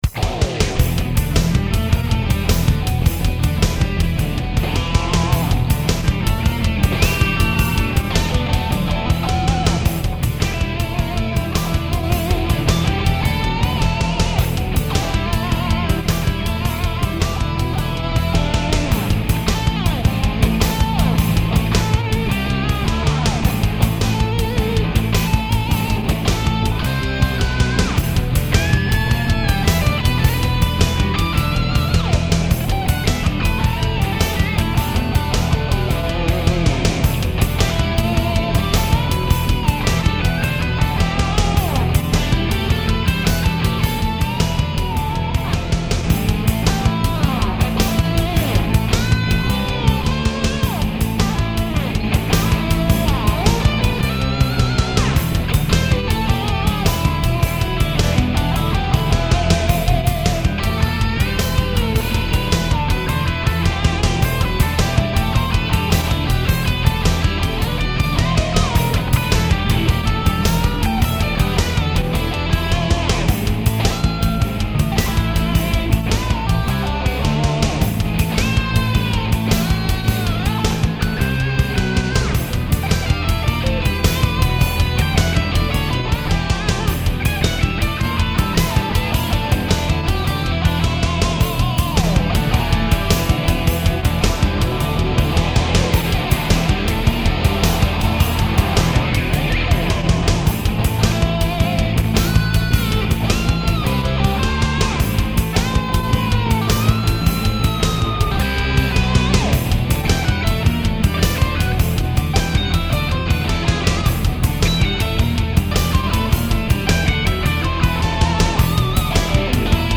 My original guitar instrumental "highlight"